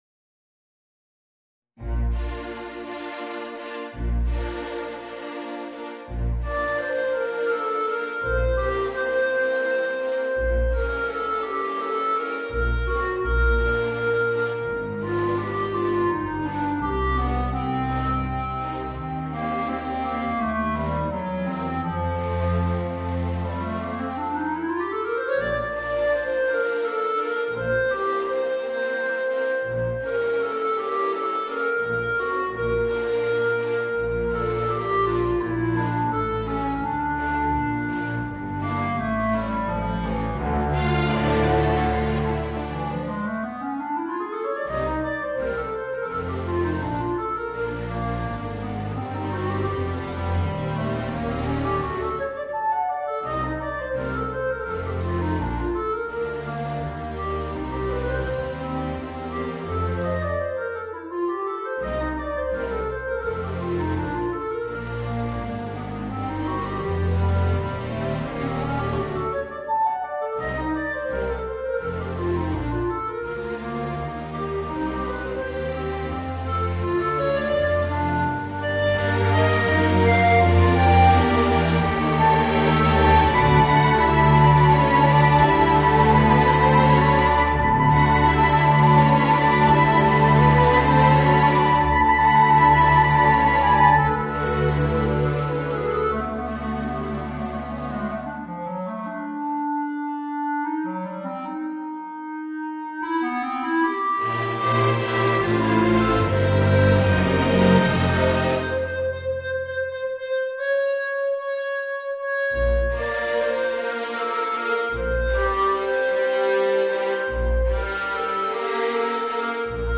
Voicing: Clarinet and String Quintet